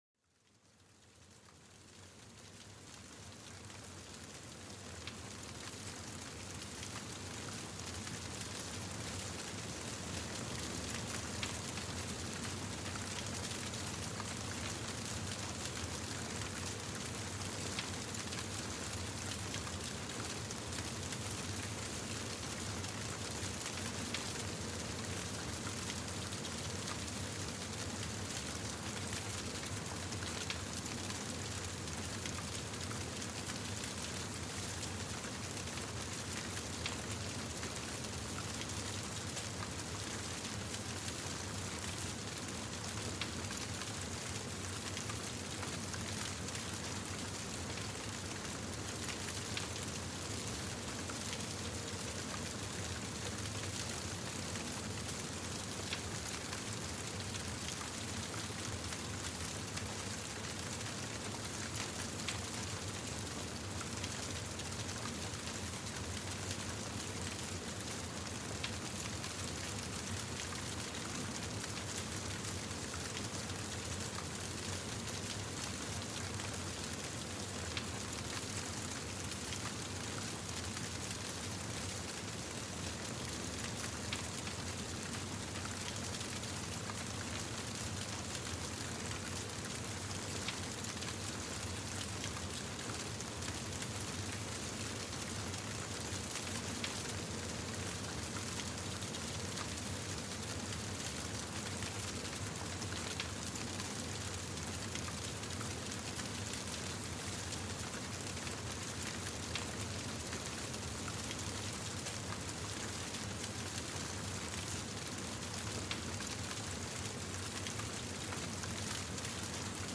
Sumérgete en este episodio único, donde el reconfortante crepitar del fuego se convierte en una sinfonía natural que apacigua el alma. Descubre cómo el sonido del fuego, con sus llamas danzantes y chispas fugaces, puede transformar una velada ordinaria en un momento de relajación profunda.